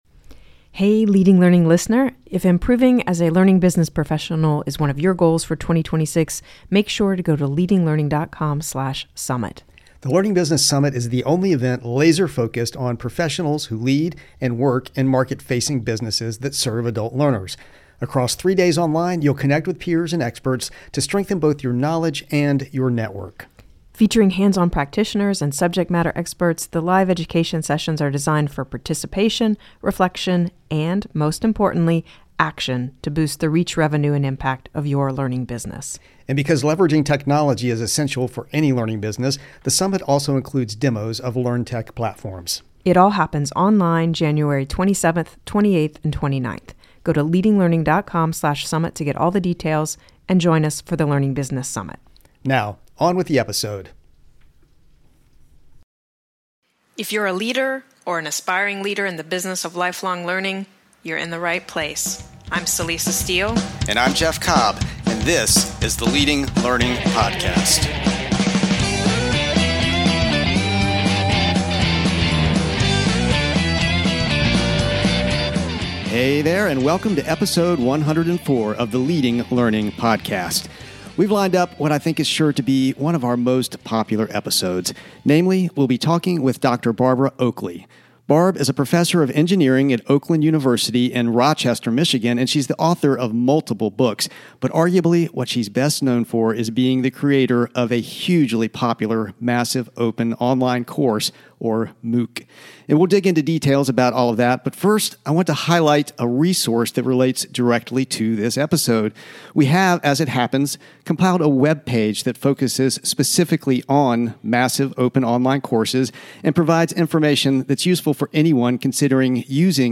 Why is helping people learn how to learn so valuable and what does it mean for your learning business? Find out in this interview with Dr. Barbara Oakely.